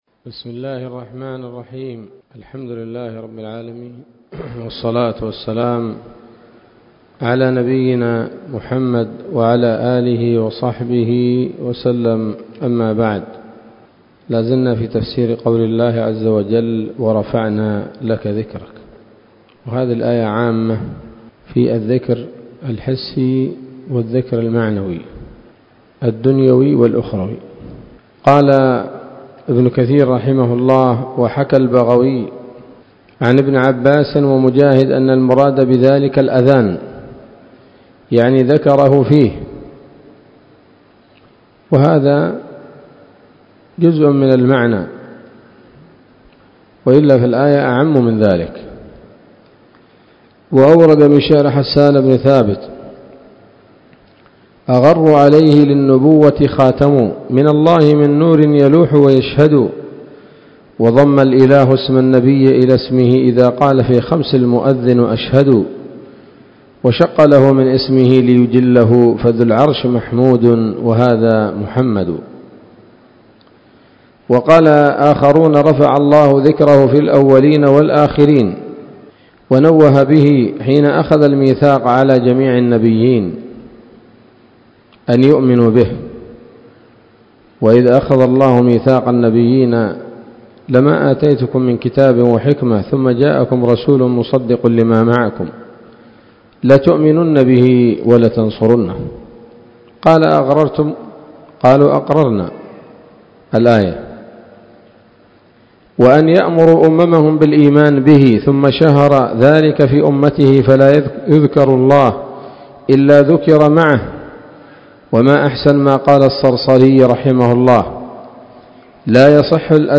الدرس الثاني وهو الأخير من سورة الشرح من تفسير ابن كثير رحمه الله تعالى